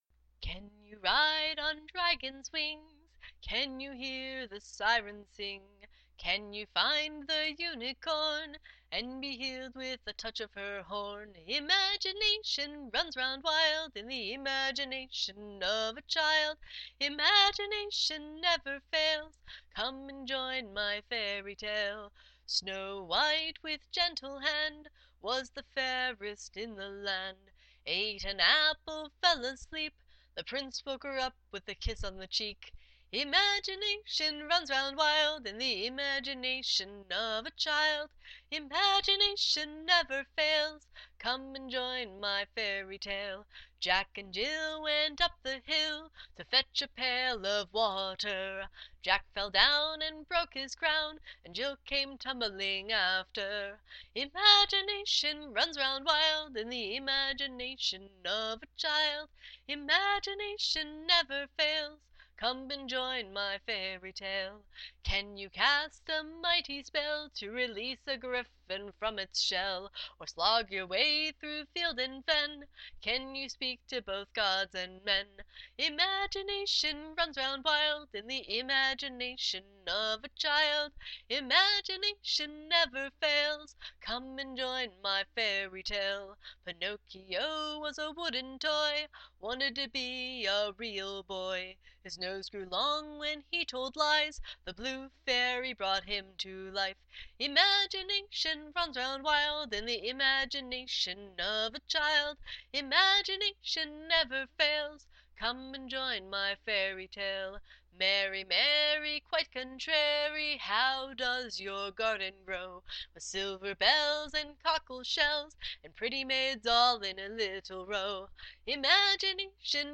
Imagination-Rough-Cut.mp3